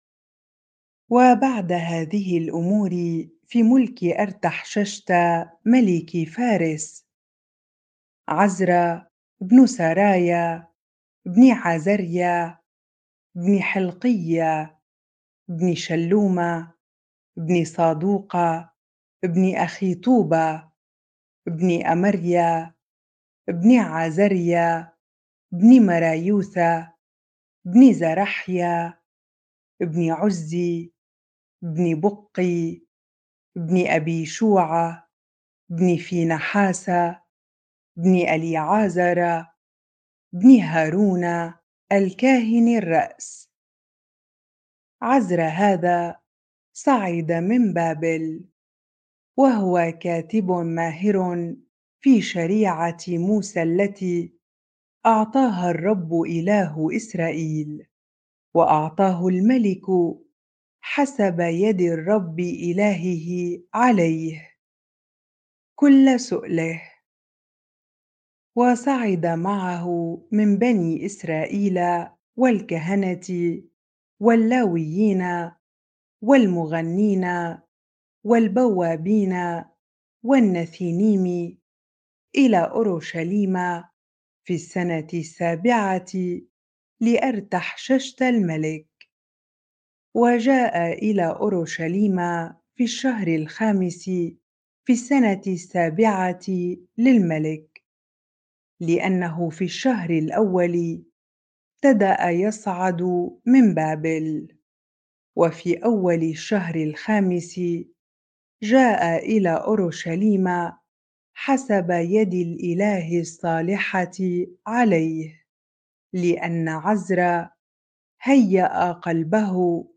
bible-reading-Ezra 7 ar